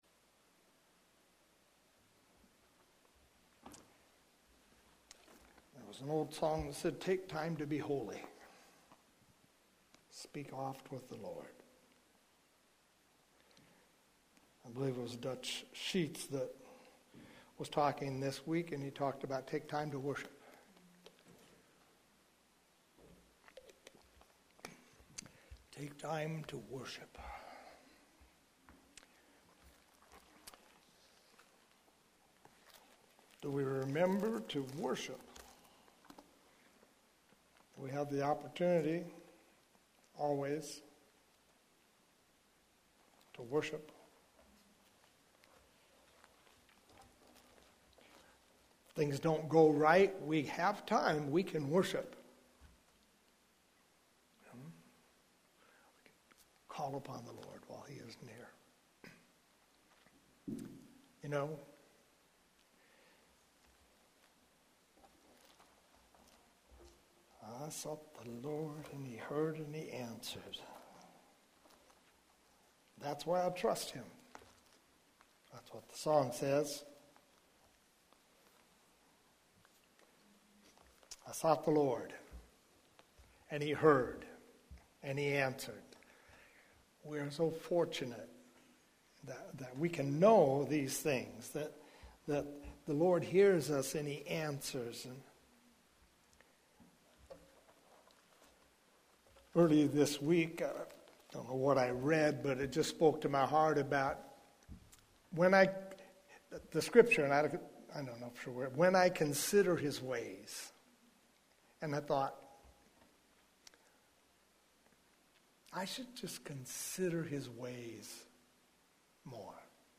Here you’ll find a selection of audio recordings from Hosanna Restoration Church.